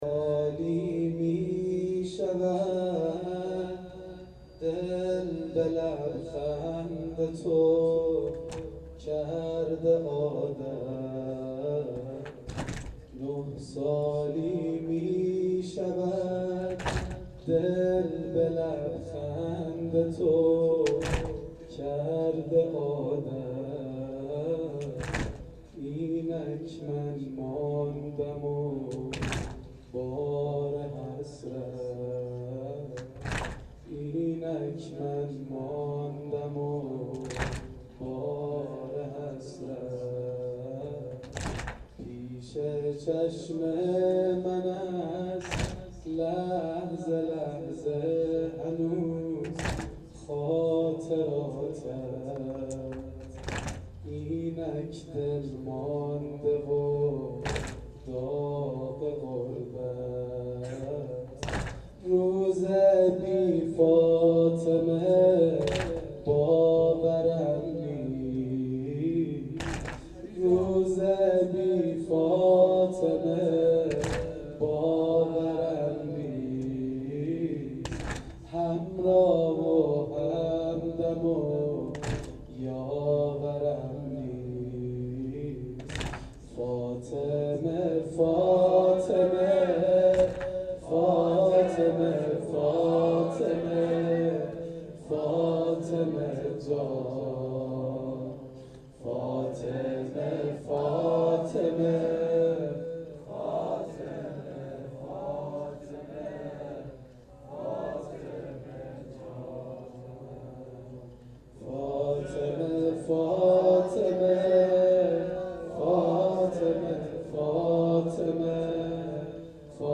واحد سنگین شب اول فاطمیه دوم